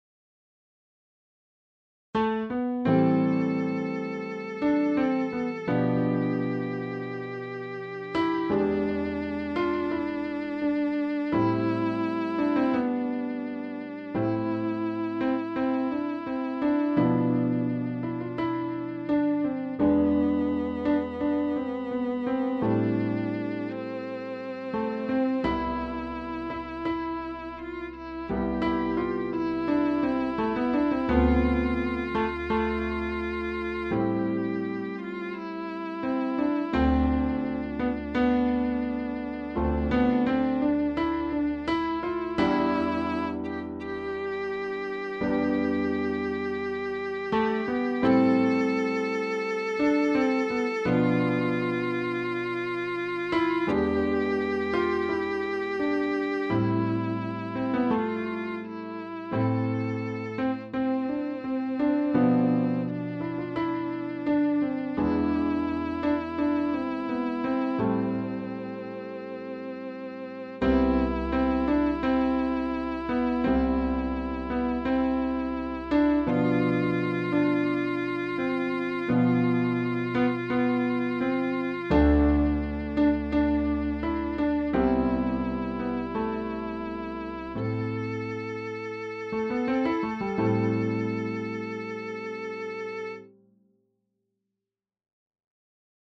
BGM
ショートジャズスローテンポ暗い